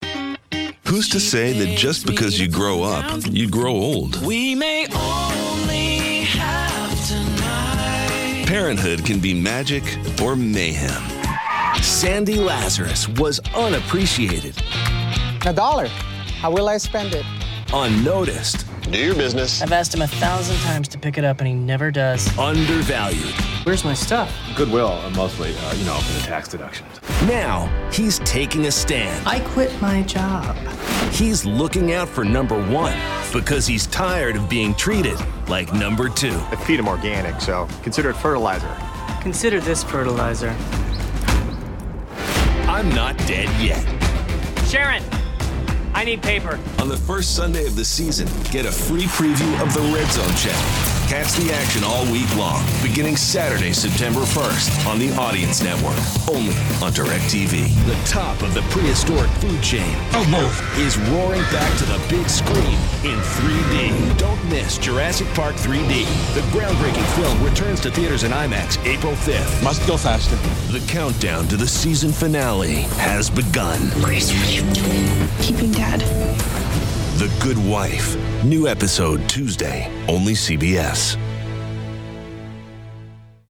Voiceover : Promo : Men